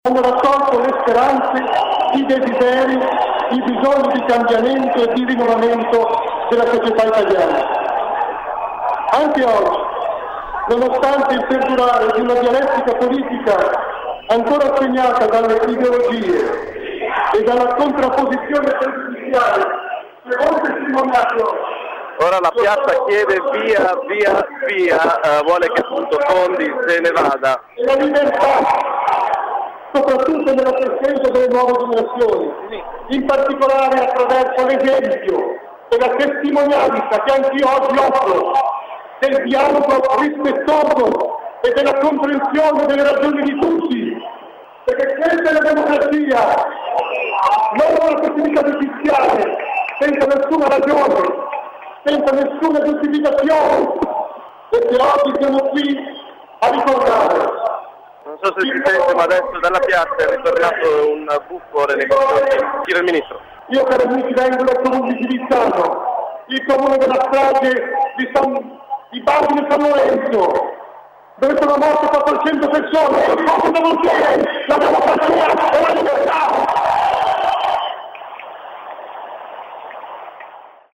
La piazza, già particolarmente calda, ha ascoltato il discorso del sindaco Delbono, poi si è infuocata quando ha preso la parola il Ministro Bondi: neanche il tempo di andare al microfono ed è partita una selva di fischi.
bondi_palco_fischi.mp3